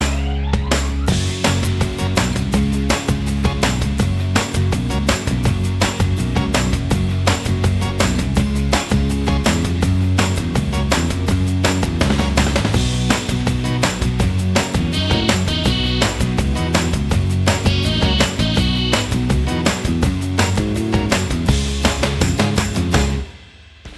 Minus All Guitars Rock 3:13 Buy £1.50